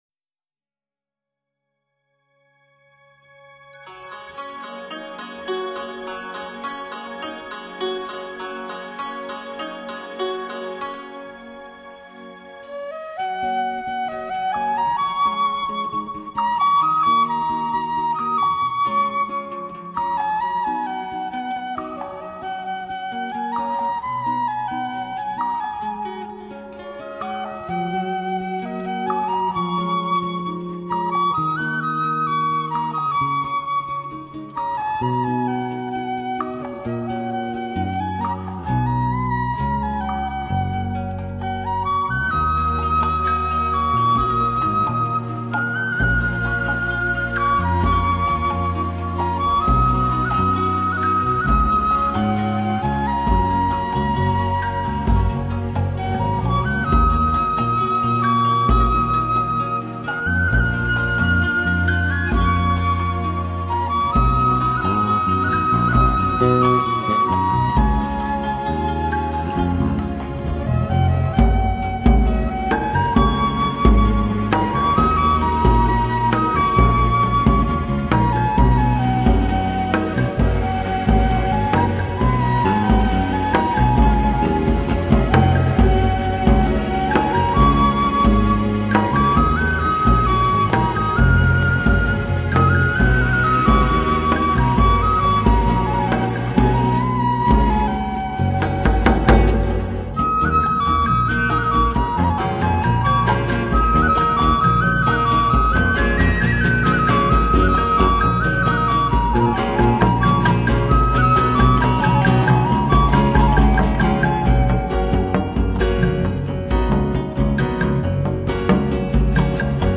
中国笛、打击乐、键盘